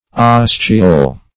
Ostiole \Os"ti*ole\, n. [L. ostiolum a little door, dim. of